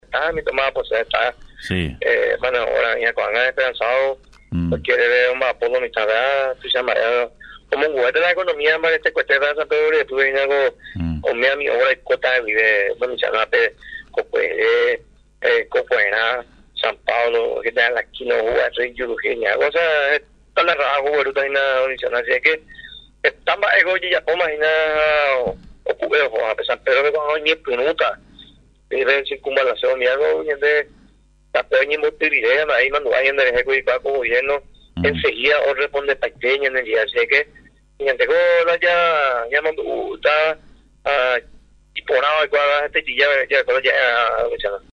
El gobernador del departamento de San Pedro, doctor Carlos Giménez, en contacto con Radio Nacional destacó la importancia de las obras viales, que se desarrollan en la región.